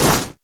default_grass_footstep.3.ogg